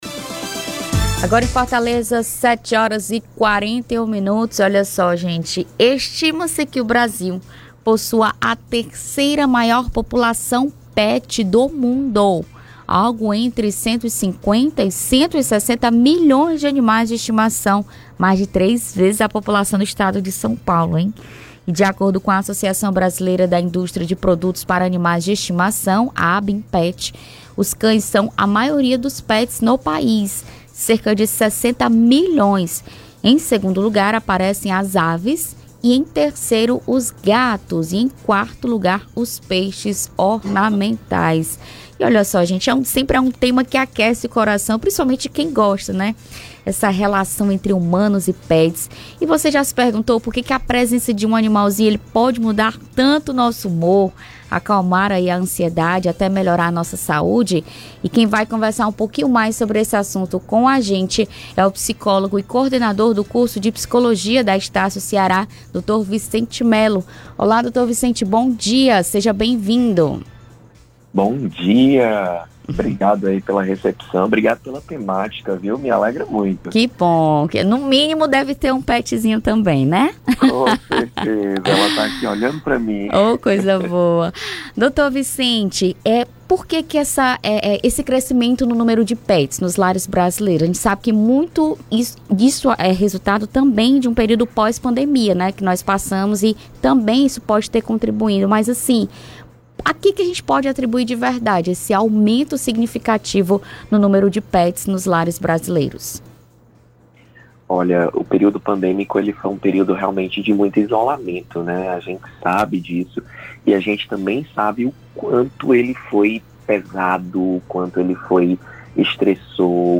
Entrevista do dia
Entrevista com psicólogo aborda impacto emocional e comportamental dos pets com os humanos; confira